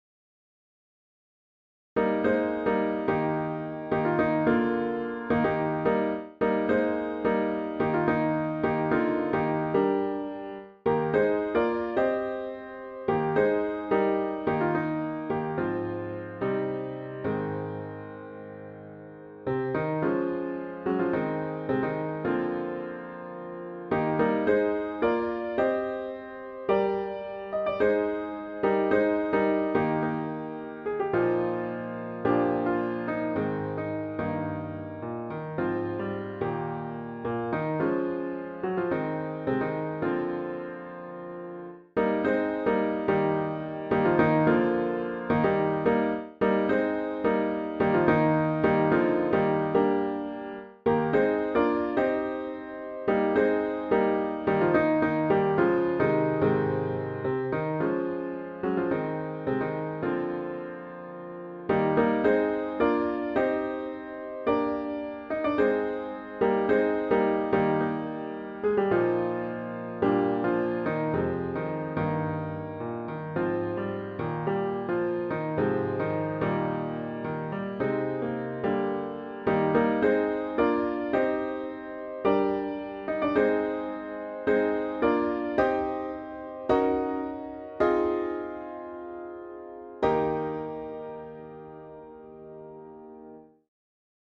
Fellowships - Soprano practice file Fellowships - Alto practice file Fellowships - Tenor practice file Fellowships - Bass (1&2) practice file Fellowships -
fellowshipsbassEb.mp3